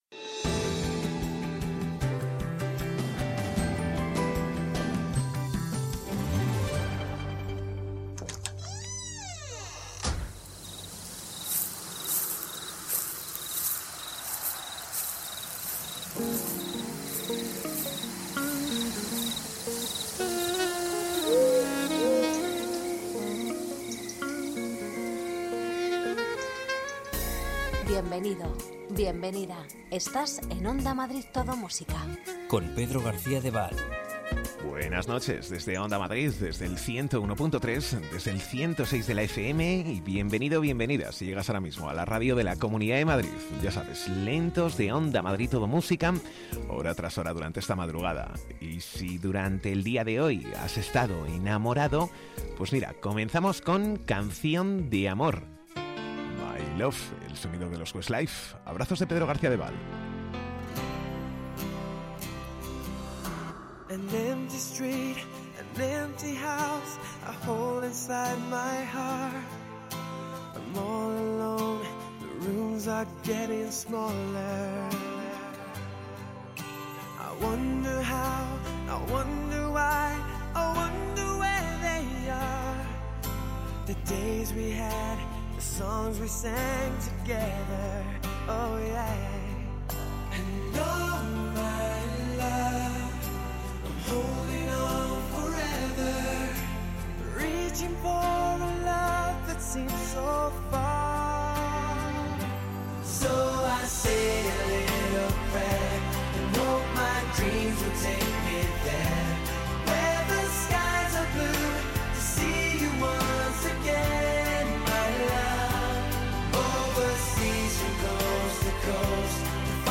Ritmo tranquilo, sosegado, sin prisas...